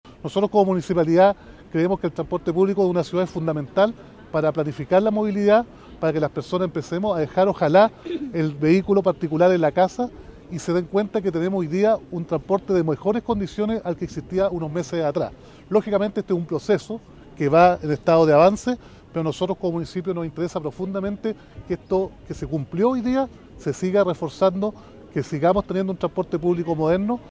El alcalde de Temuco, Roberto Neira, dijo que con el sistema de recaudo electrónico y anteriormente con el perímetro de exclusión, el transporte público se va modernizando.